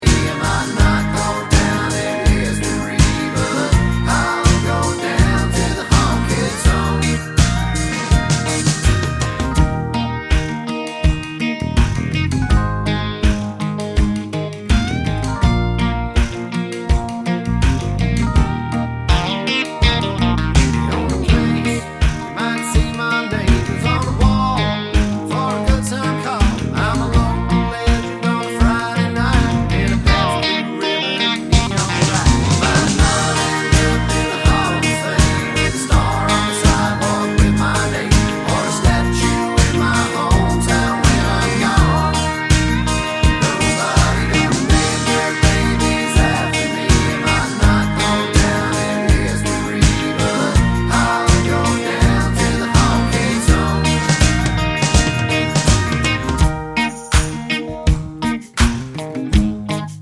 no Backing Vocals Country (Male) 3:00 Buy £1.50